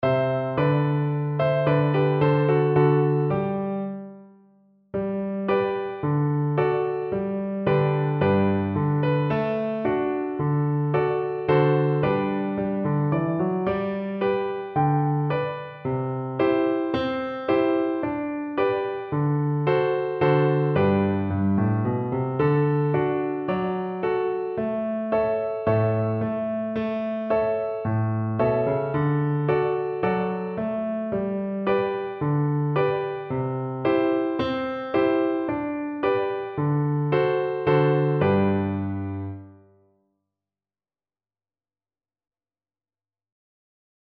Classical Trad. O alte Burschenherrlichkeit Viola version
Viola
Traditional Music of unknown author.
G major (Sounding Pitch) (View more G major Music for Viola )
Moderately fast =c.110
4/4 (View more 4/4 Music)